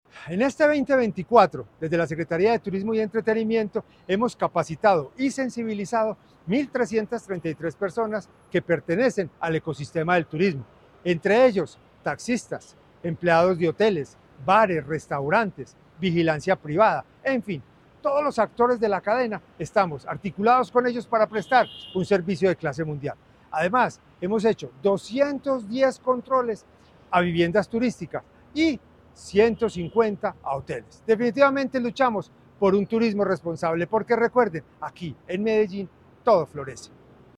Palabras de José Alejandro González, secretario de Turismo y Entretenimiento